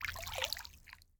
water-splash-12-2
bath bathroom bubble burp click drain dribble dripping sound effect free sound royalty free Nature